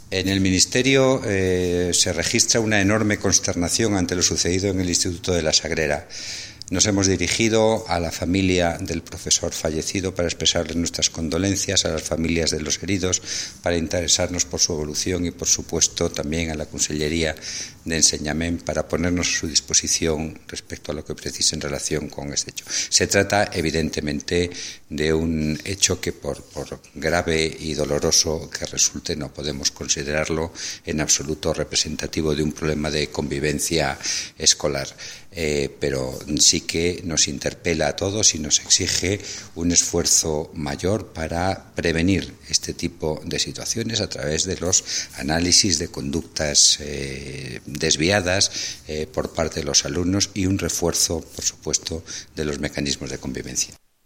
Declaraciones del ministro de Educación, Cultura y Deporte, José Ignacio Wert.